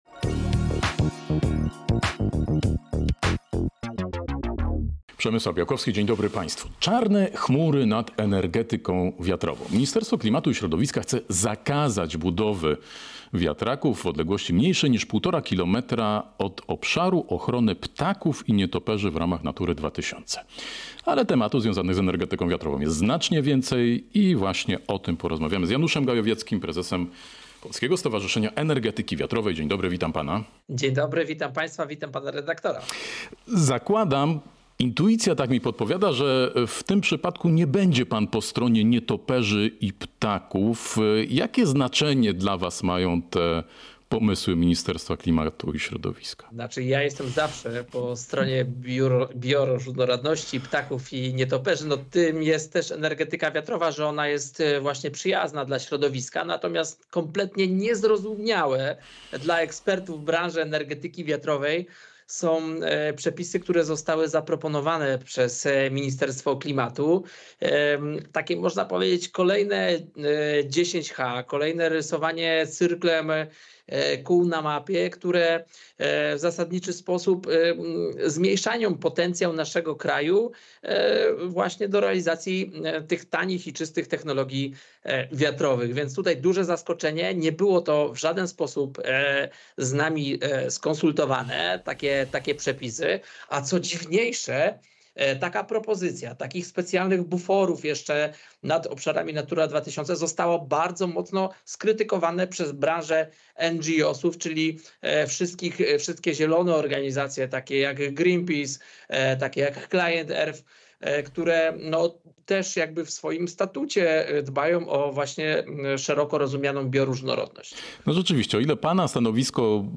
Cała rozmowa jest dostępna w serwisie Zielona Interia.